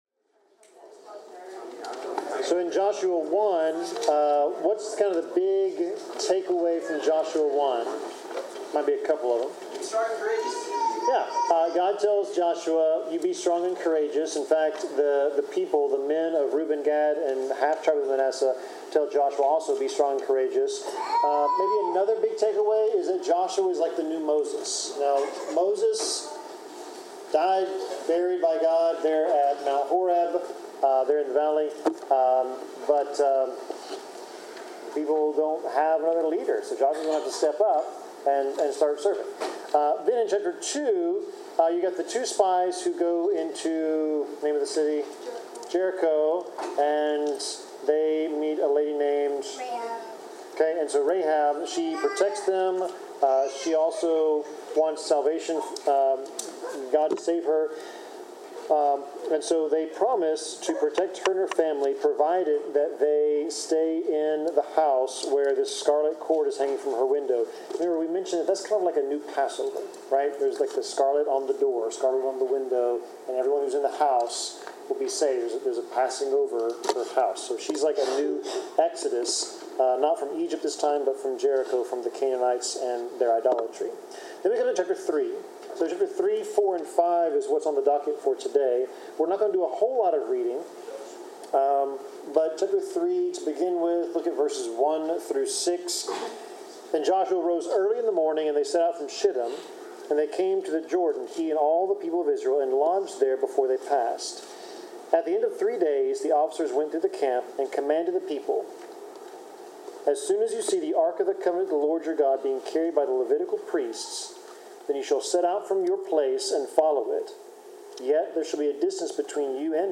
Bible class: Joshua 3-5
Service Type: Bible Class Topics: Deliverance , Faith , God's Authority , God's Leadership , Memorial , Obedience , Promises of God , Prophecy , Relationship with God , Salvation , Trusting in God « Acts 16